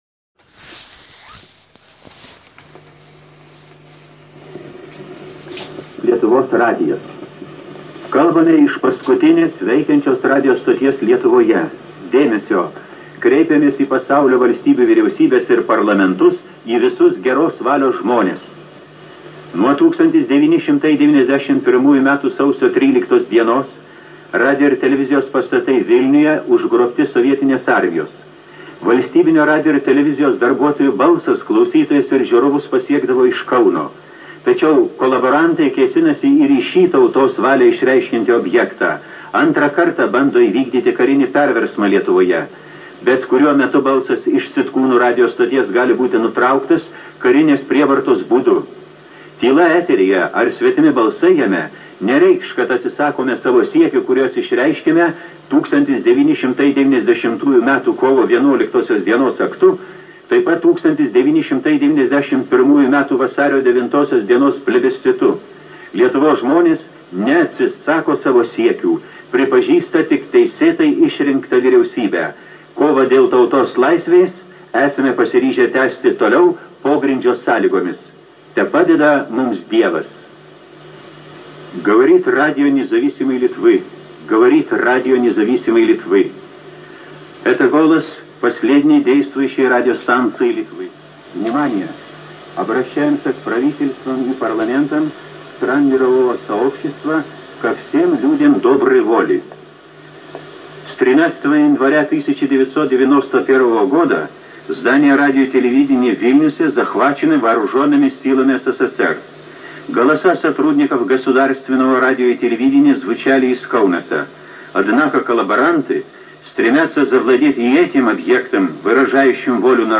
Radijo pranešimų tekstai – Sitkūnai
Radijo pranešimų tekstai lietuvių, anglų, prancūzų, vokiečių, lenkų ir rusų kalbomis.
Tikslios transliacijos datos – ar Sausio 13-ąją, ar per Maskvos karinį pučą 1991 m. rugpjūčio mėnesį, – nežinomos.